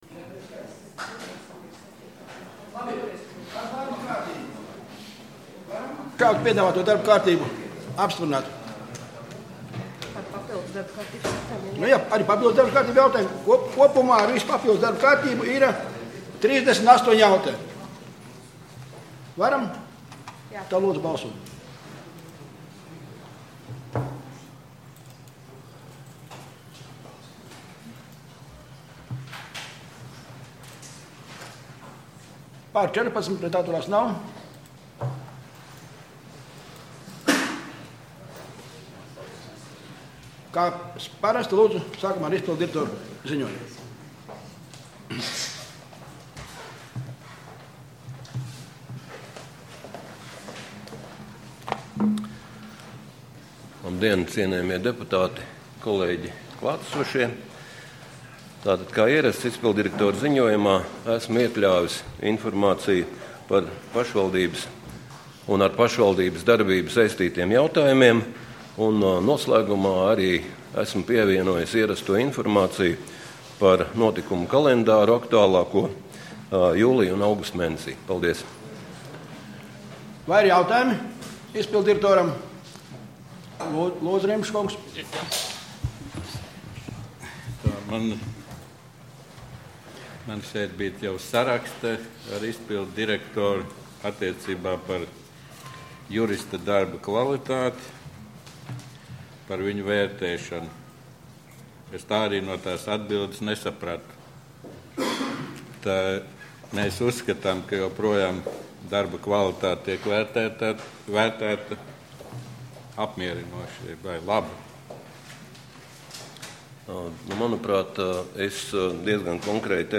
Domes sēde Nr. 11